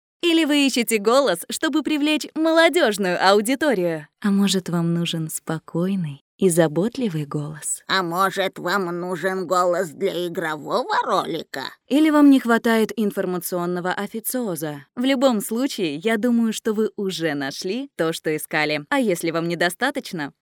Group A         Russian female voice overs. Group A